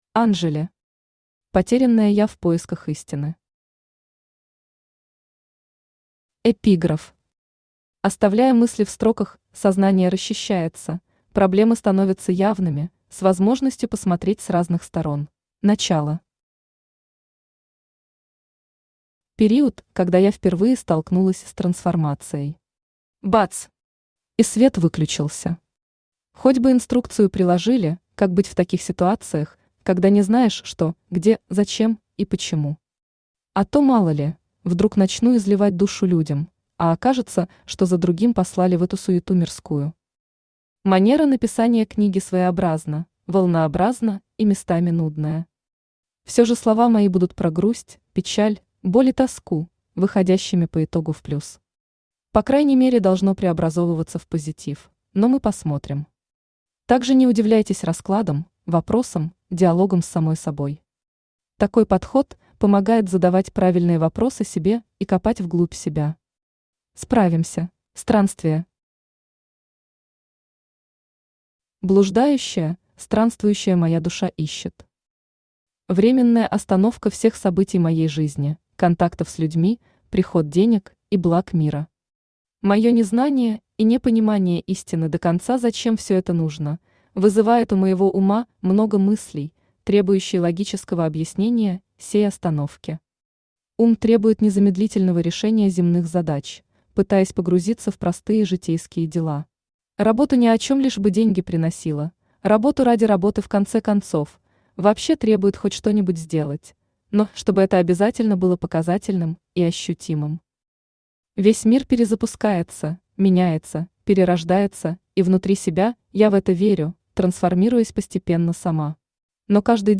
Aудиокнига Потерянное Я в поисках истины Автор Анжели Читает аудиокнигу Авточтец ЛитРес.